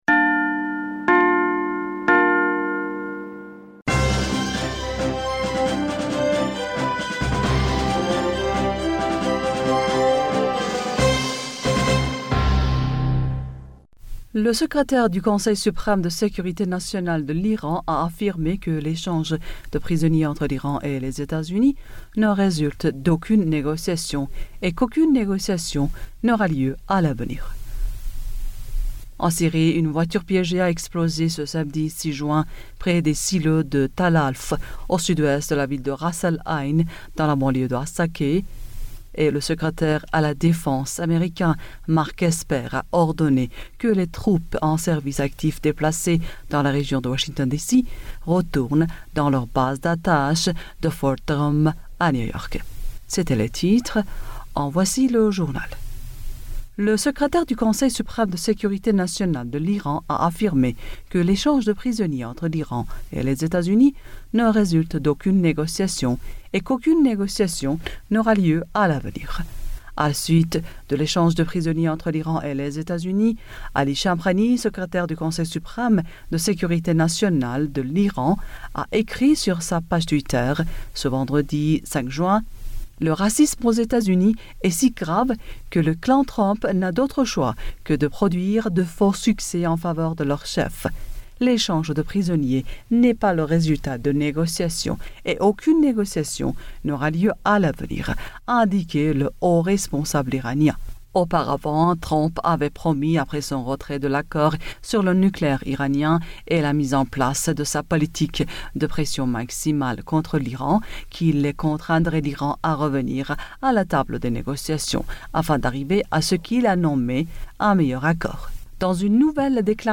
Bulletin d'information du 06 Juin 2020